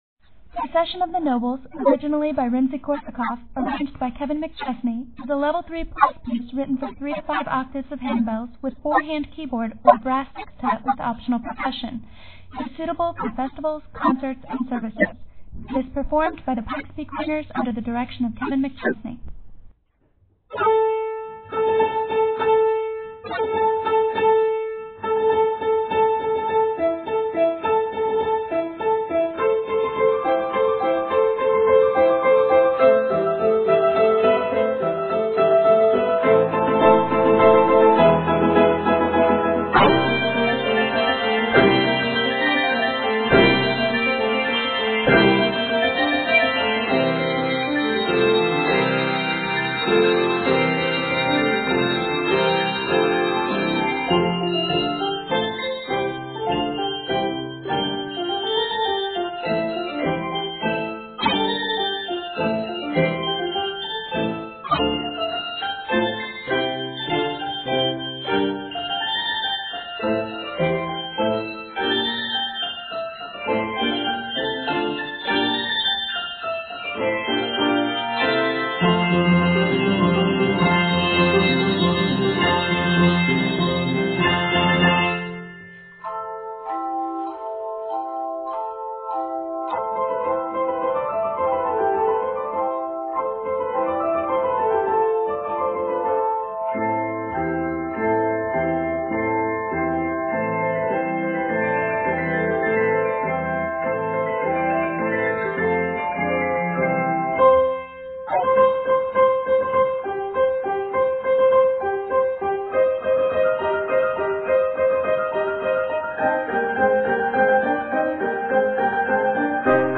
big, bold, and festive